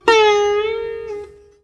SITAR LINE62.wav